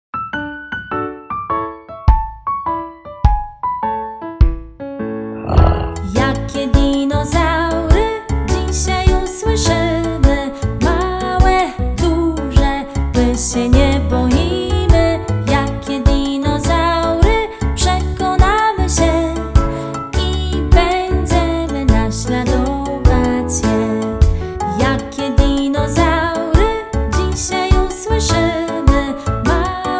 utwór w wersji wokalnej